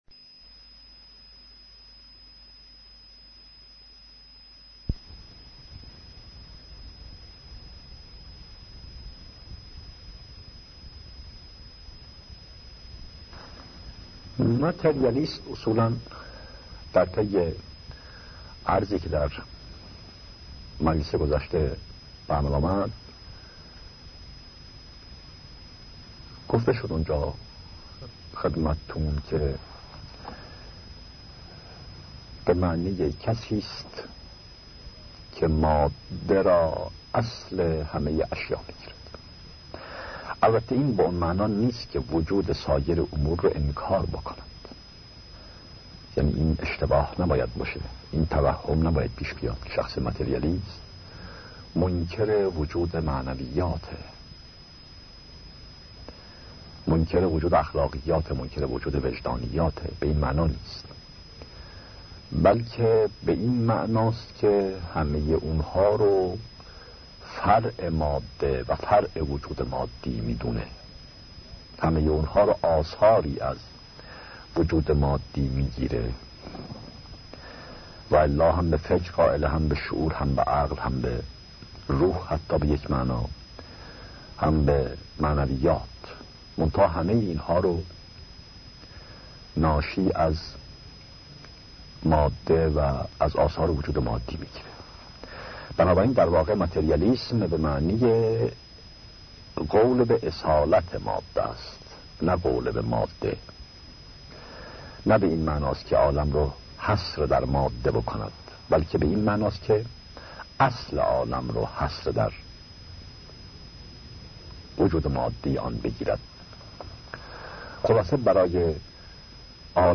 دانلود قسمت ششم سایر دسته بندیها سخنرانی هایی پیرامون عقاید بهائی